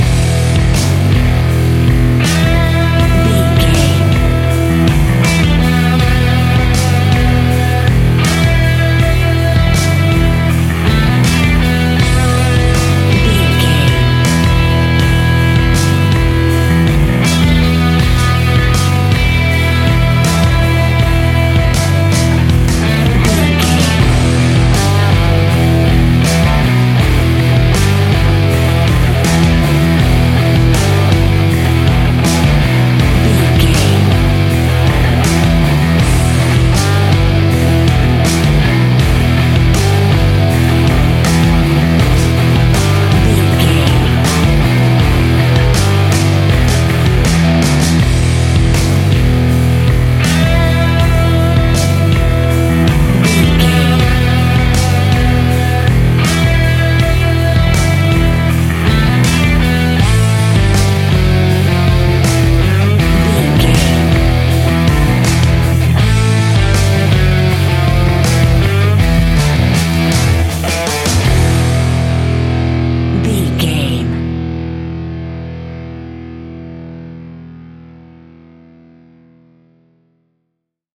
Epic / Action
Aeolian/Minor
hard rock
heavy metal
blues rock
instrumentals
Rock Bass
heavy drums
distorted guitars
hammond organ